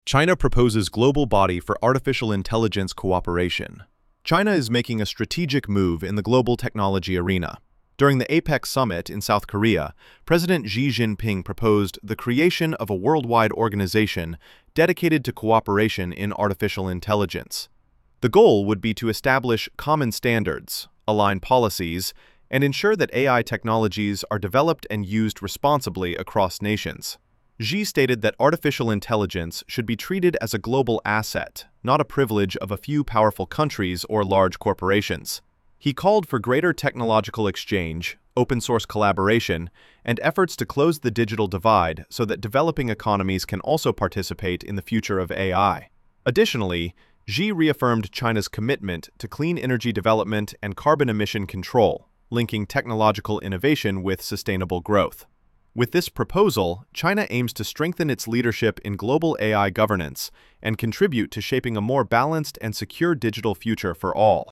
Diplomatic narration · MP3 · ~70–90 seconds